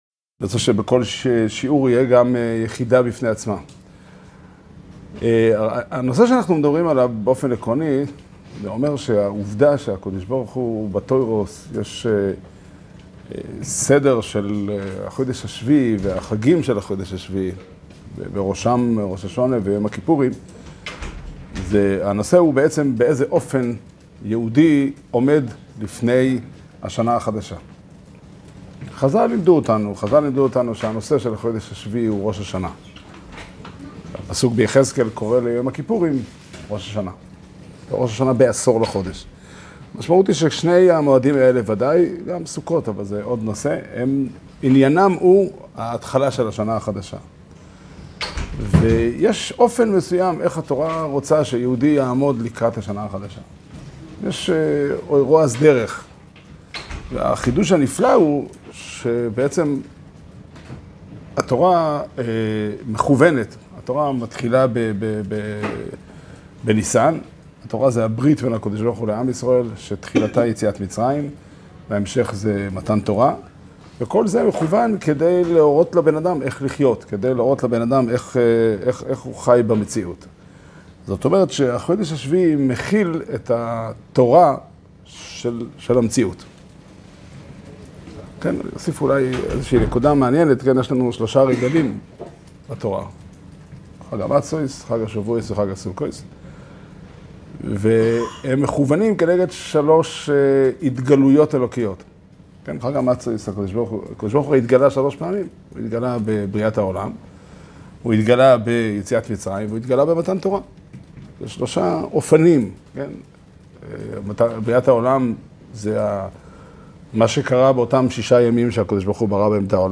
שיעור שנמסר בבית המדרש פתחי עולם בתאריך ט"ז אלול תשפ"ד